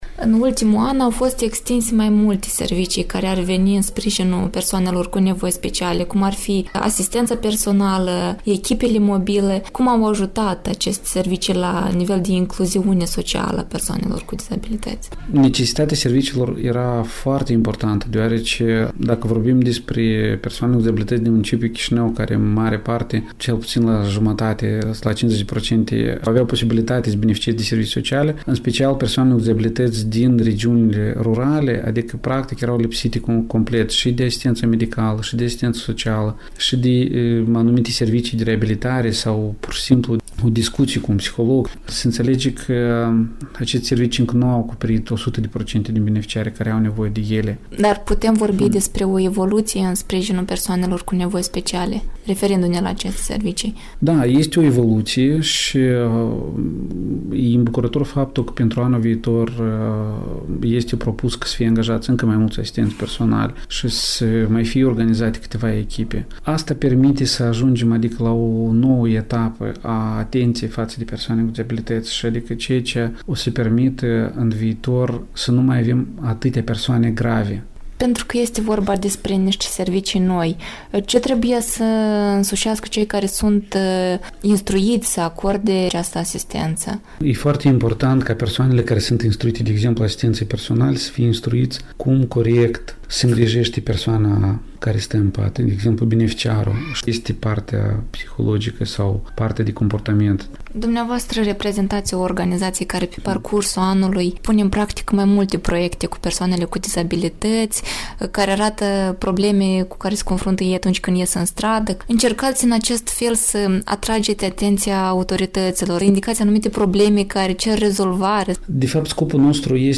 Un interviu bilanț